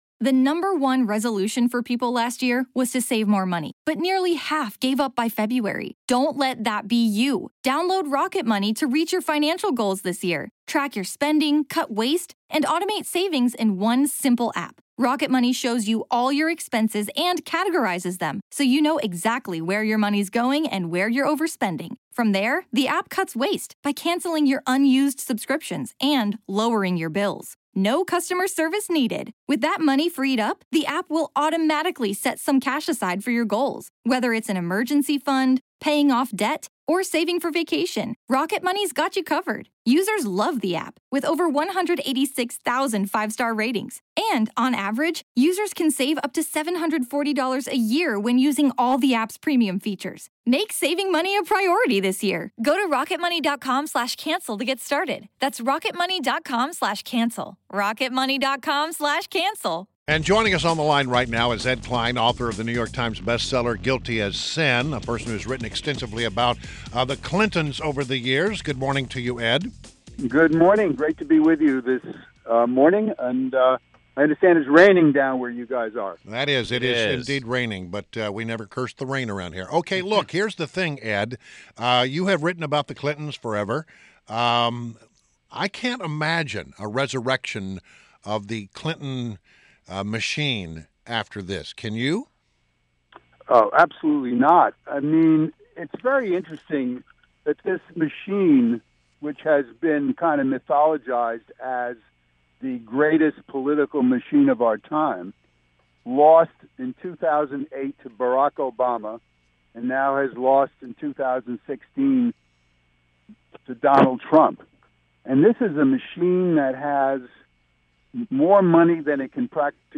WMAL Interview - ED KLEIN - 11.09.16